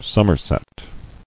[sum·mer·set]